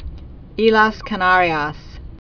(ēsläs kä-näryäs)